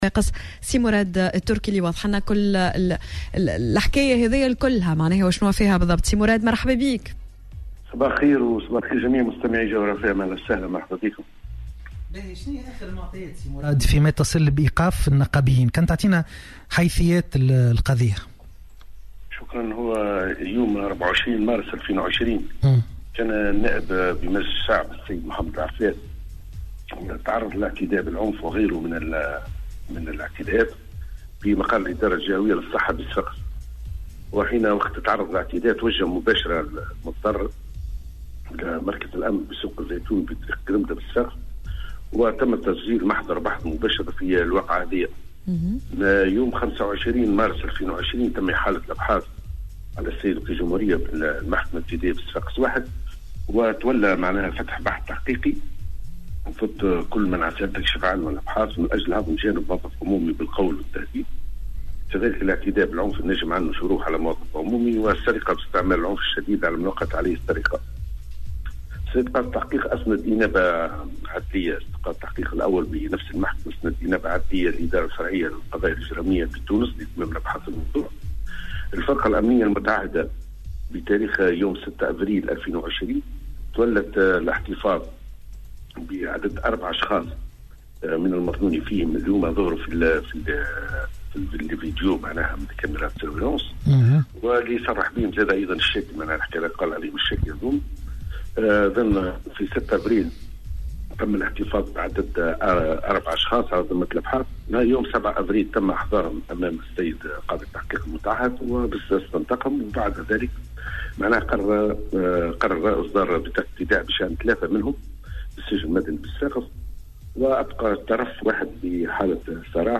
وأوضح في مداخلة هاتفية مع "صباح الورد" على "الجوهرة اف اف ام" انه على اثر ايداع النائب المتضرر شكاية لدى الجهات الأمنية بالجهة يوم 24 مارس الماضي اثر تعرضه لاعتداء بالعنف في مقر الادارة الجهوية بصفاقس تم فتح بحث تحقيقي من أجل هضم جانب موظف عمومي بالقول والتهديد والاعتداء بالعنف على موظف عمومي والسرقة باستعمال العنف الشديد، مضيفا أن الابحاث متواصلة مع السماعات لعدة أطراف أخرى في انتظار استكمال الأبحاث.